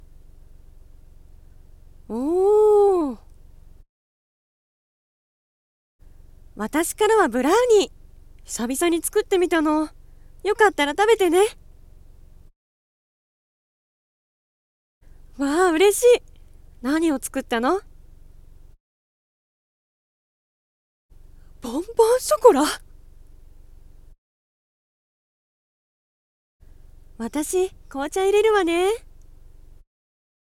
💜バレンタイン声劇 nanaRepeat